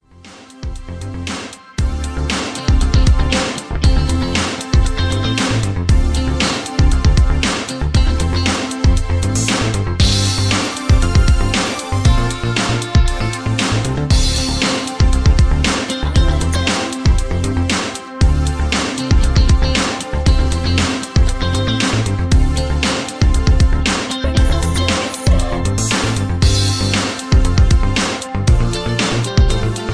Key-Dm) Karaoke MP3 Backing Tracks
Just Plain & Simply "GREAT MUSIC" (No Lyrics).